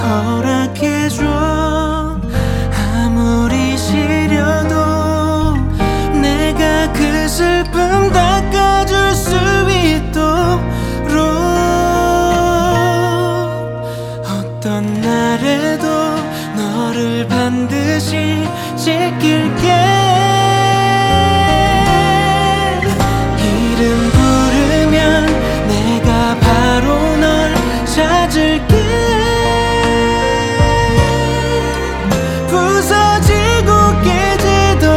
K-Pop Pop Soundtrack
Жанр: Поп музыка / Соундтрэки